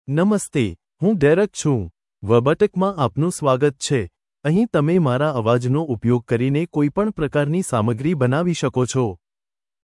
Derek — Male Gujarati (India) AI Voice | TTS, Voice Cloning & Video | Verbatik AI
Derek is a male AI voice for Gujarati (India).
Voice sample
Listen to Derek's male Gujarati voice.
Derek delivers clear pronunciation with authentic India Gujarati intonation, making your content sound professionally produced.